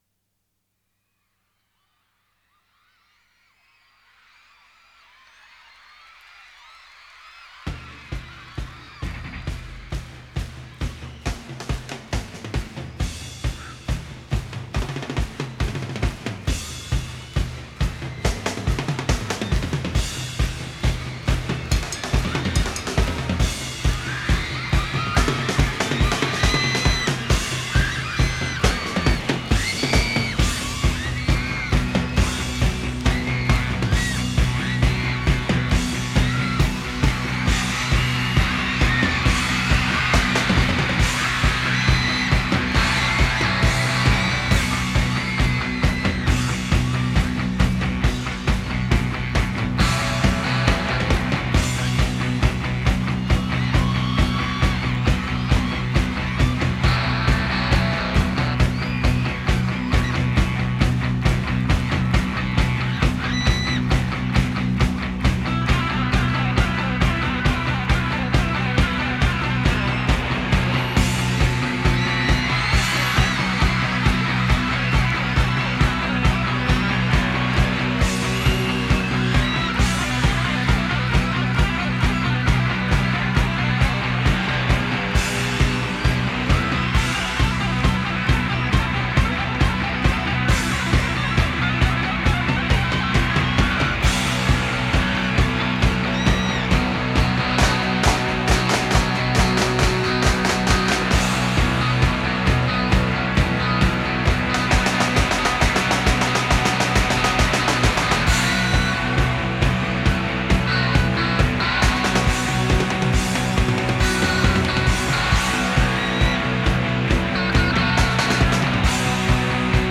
live album
guitarist